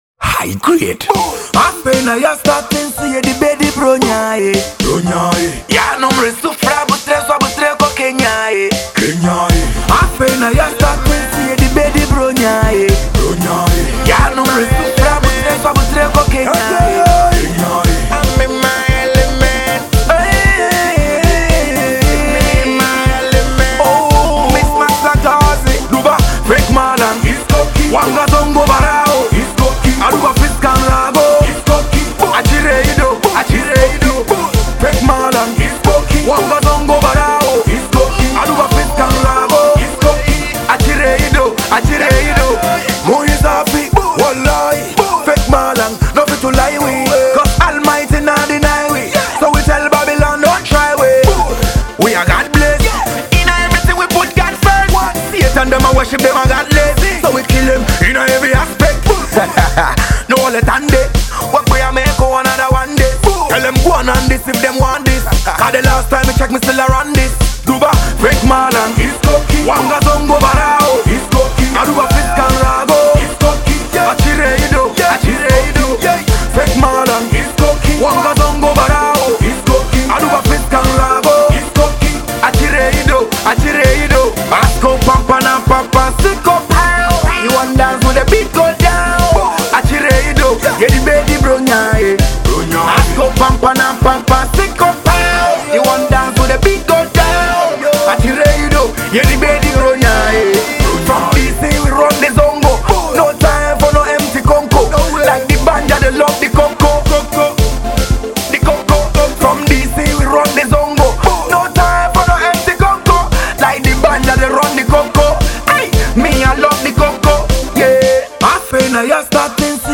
Genre: Dancehall / Afro-Fusion